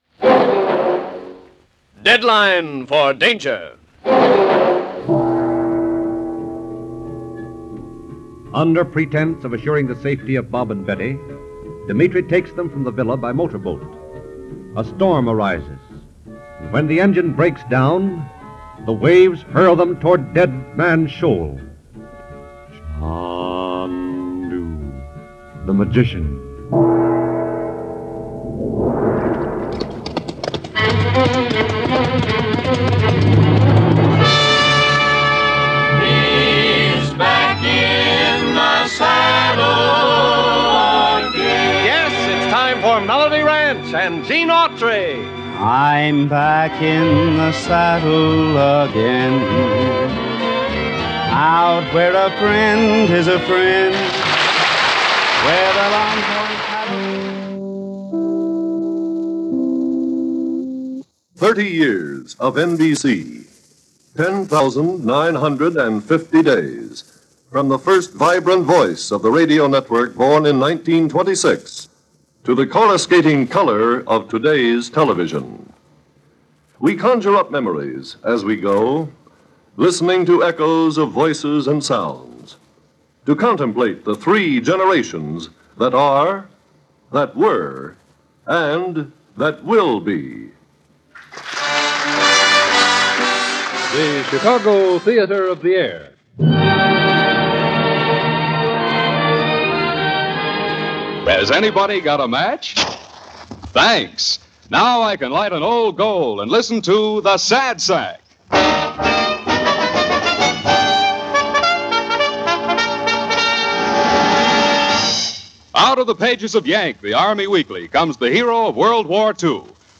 You'll find rare and obscure as well as mainstream radio shows from the 1930s, 1940s, and 1950s in the Radio Archives Treasures sets. These shows have all been restored with state-of-the-art CEDAR technology - the audio processing system used by major recording companies to restore older recordings. We expect the shows to be the best sounding copies available anywhere.